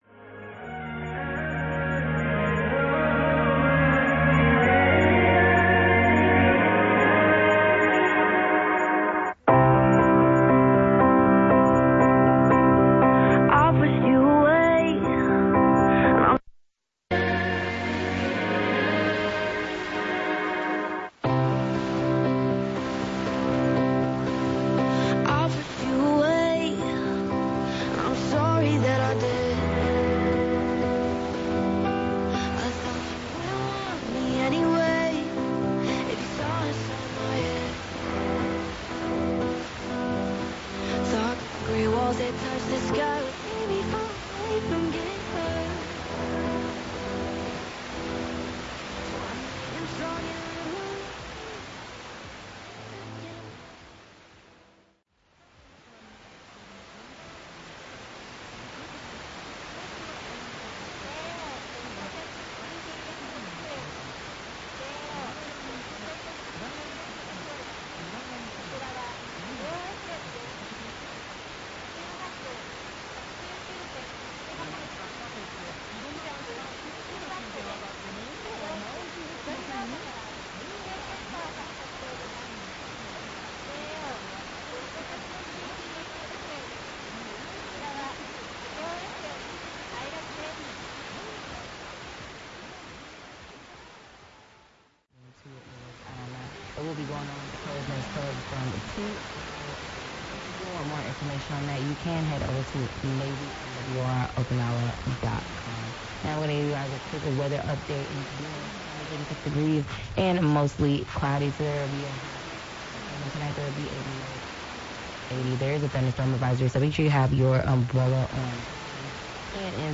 10日12時台、89.1ハックワンで弱くあいラジ上尾試験電波が聞こえておりました。
埼玉県戸田市の荒川河川敷の木陰受信ポイントでは土手で電波が遮られるのか、あいラジ上尾試験電波は弱いです。
89.1MHzのAFN沖縄は12秒遅れのパラを確認しました。
・00:55-01:35 1228’35“-1229’15“ 89.1MHz あいラジ上尾試験電波アナウンス
<受信地：埼玉県戸田市 荒川河川敷 RX:ICF-SW7600GR ANT:Built-in whip>
※00:09- 810kHz AFN東京音楽開始
※00:21- 89.1MHz AFN沖縄音楽開始
※同じ音楽開始時間に12秒の差があります。